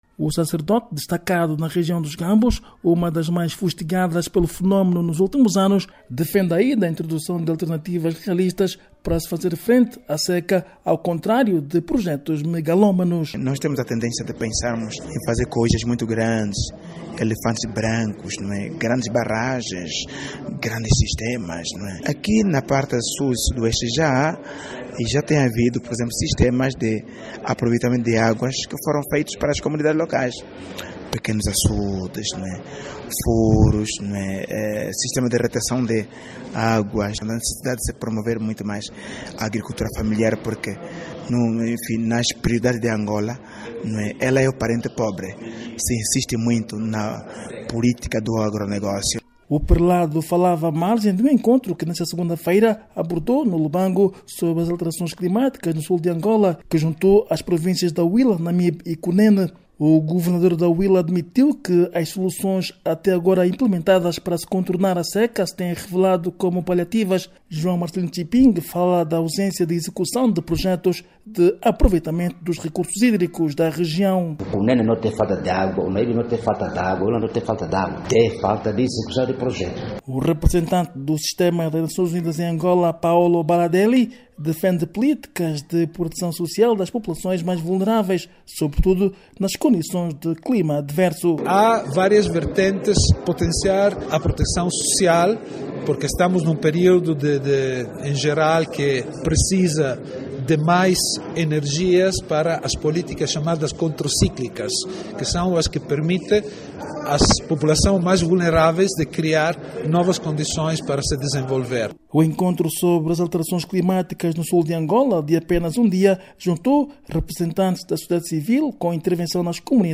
Debate sobre combate à seca na Huíla - 2:09